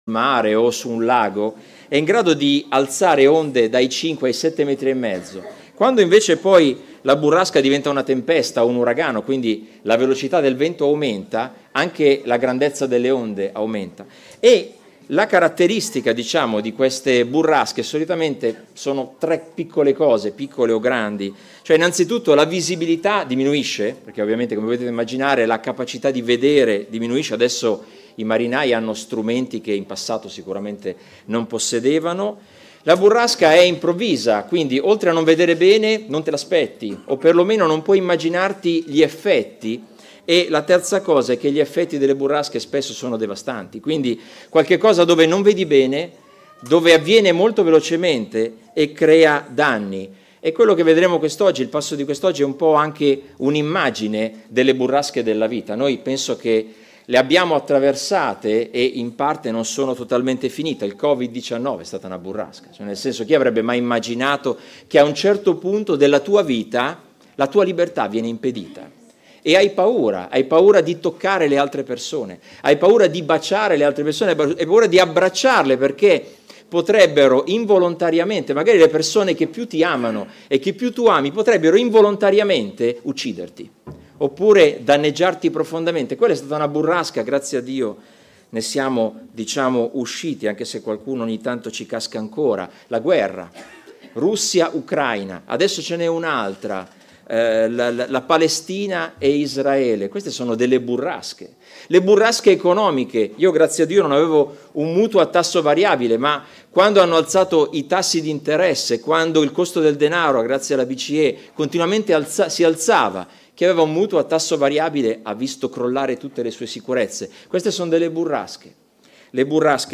Tutti i sermoni